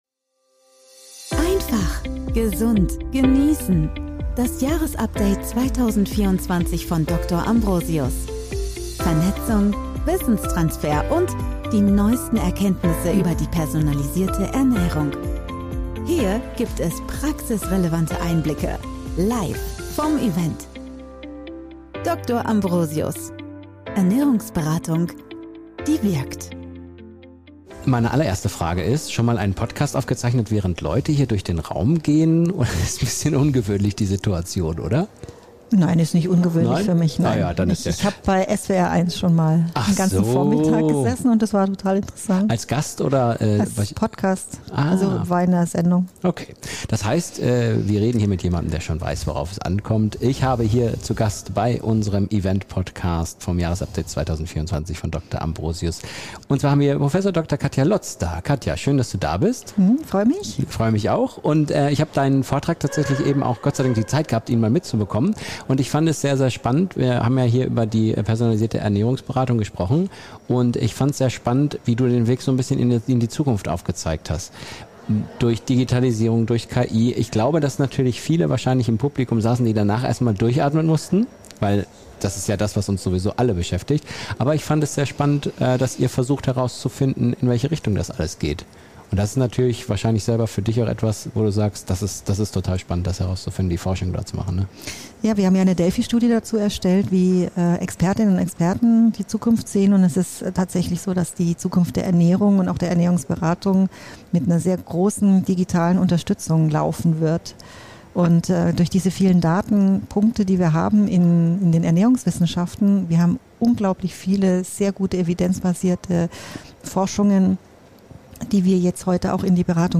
Digitalisierung und Personalisierte Ernährung - Im Gespräch
In dieser Live-Podcastfolge vom DR. AMBROSIUS Jahresupdate 2024